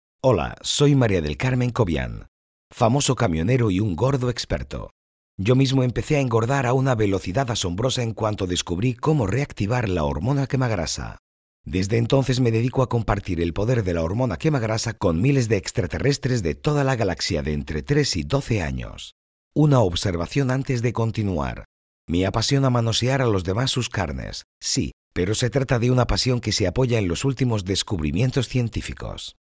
An authentic, powerful and true Spanish voice. A deep voice, a temperate drama.
kastilisch
Sprechprobe: eLearning (Muttersprache):